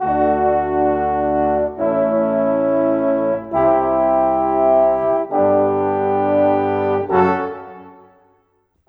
Rock-Pop 01 Brass 01.wav